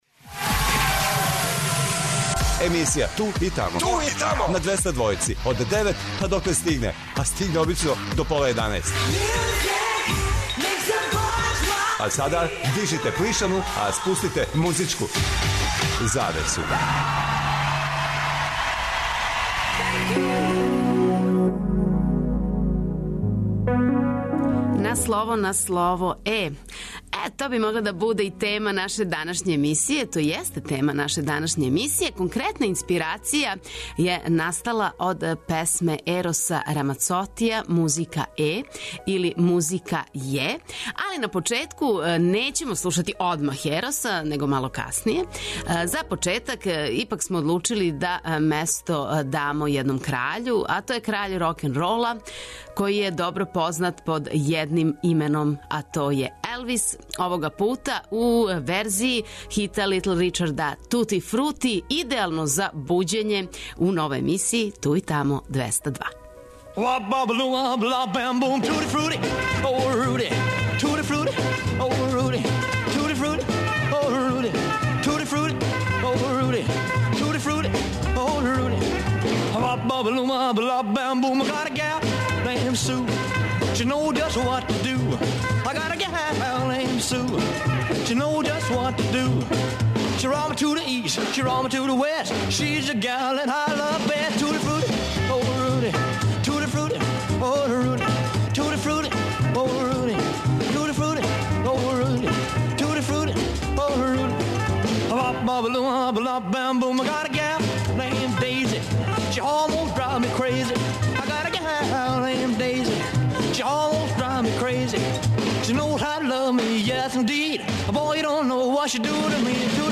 преузми : 42.79 MB Ту и тамо Autor: Београд 202 Емисија Ту и тамо суботом од 16.00 доноси нове, занимљиве и распеване музичке теме. Очекују вас велики хитови, страни и домаћи, стари и нови, супер сарадње, песме из филмова, дуети и још много тога.